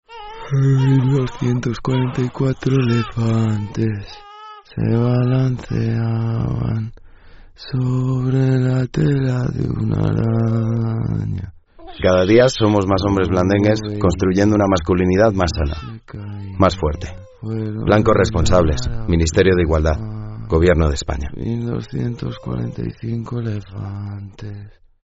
Cuña radiofónica